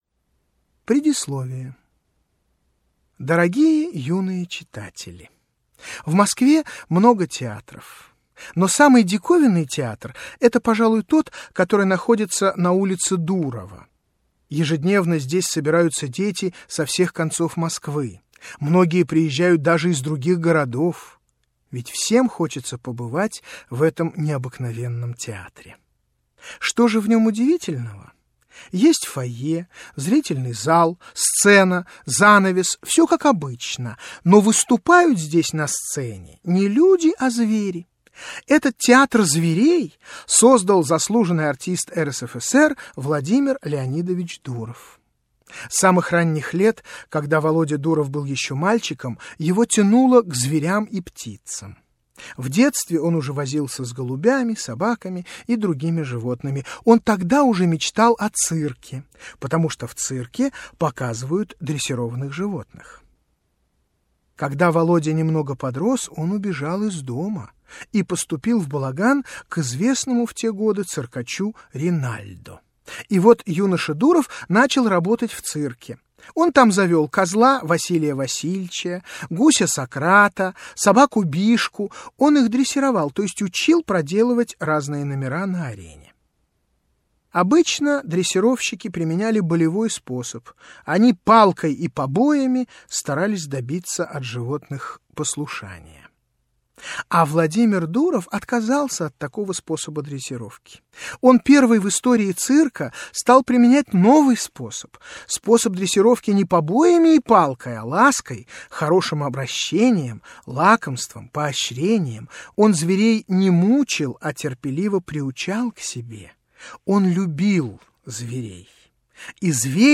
Aудиокнига Мои звери Автор Владимир Дуров Читает аудиокнигу Павел Любимцев.